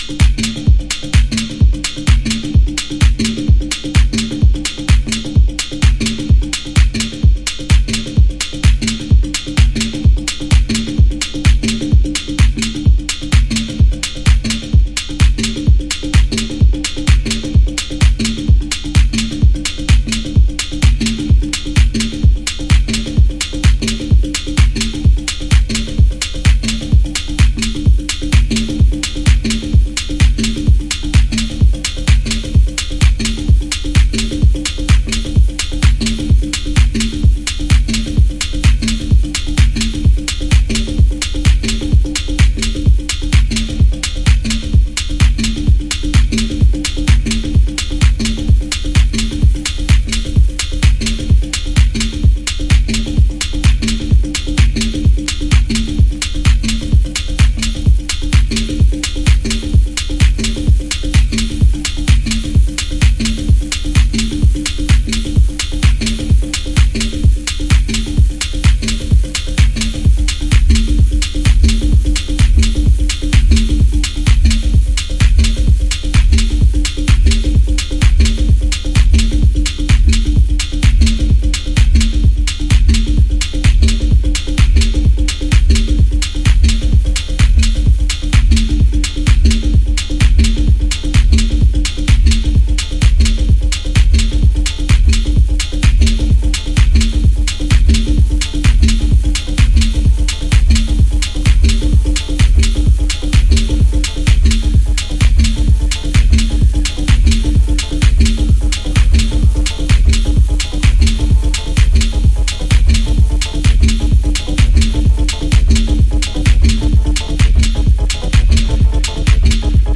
House / Techno
プリミティブなマシーンテクノ。